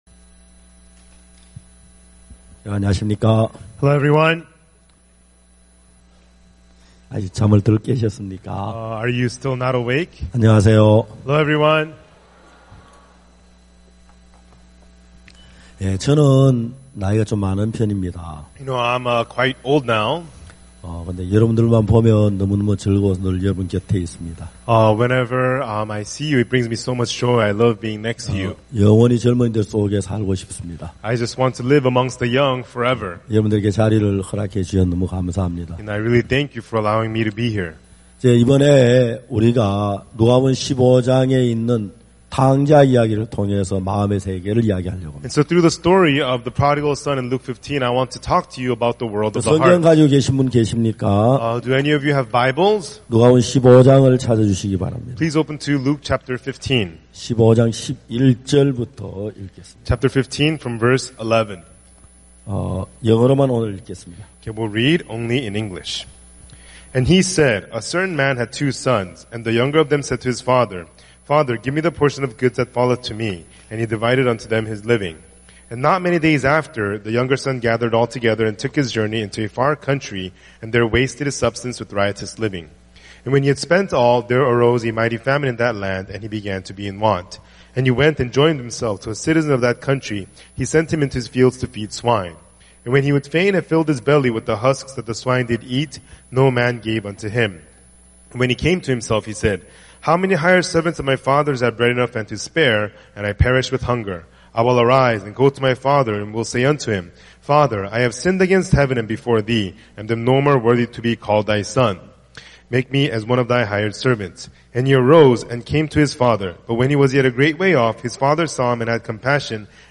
IYF 월드캠프의 주요 프로그램인 마인드 강연. 진정한 삶의 의미를 찾지 못하고 스스로에게 갇혀 방황하는 청소년들에게 어디에서도 배울 수 없는 마음의 세계, 그리고 다른 사람들과 마음을 나누는 방법을 가르친다.